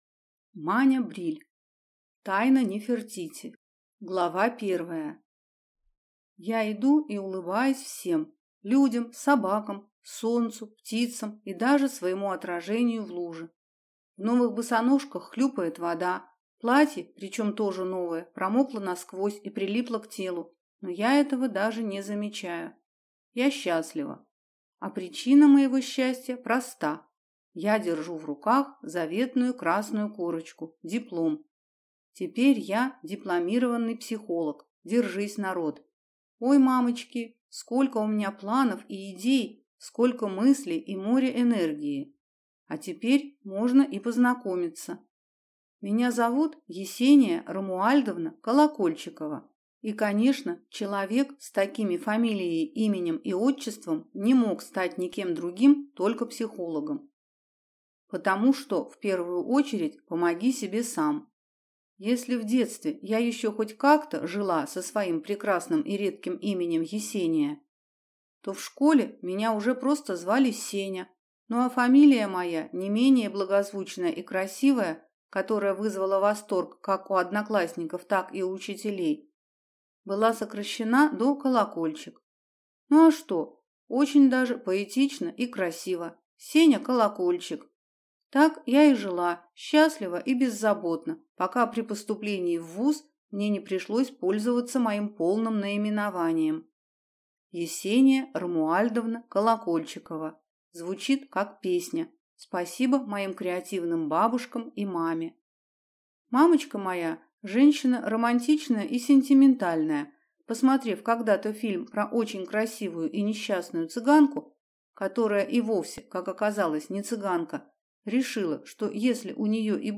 Аудиокнига Тайна Нефертити | Библиотека аудиокниг